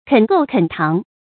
肯構肯堂 注音： ㄎㄣˇ ㄍㄡˋ ㄎㄣˇ ㄊㄤˊ 讀音讀法： 意思解釋： 堂：立堂基；構：蓋屋。